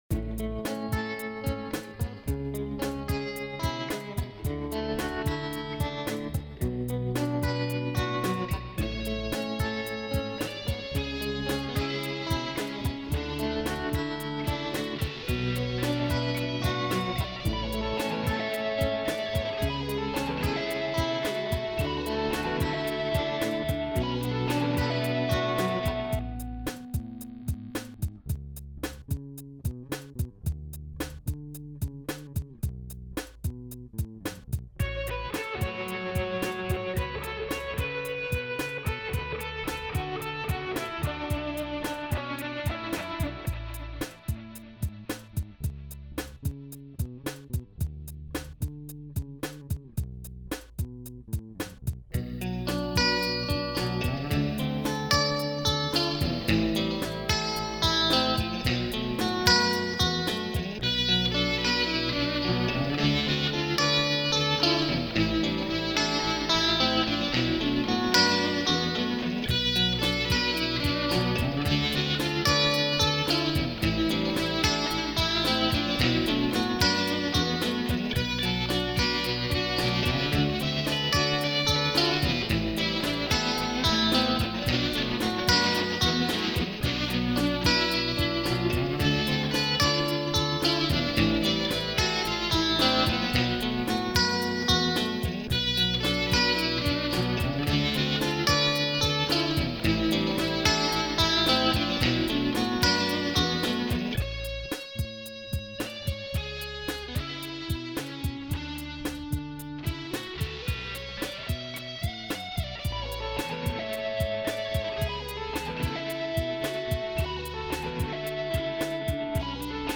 ça y'est (mais y'a pas de paroles)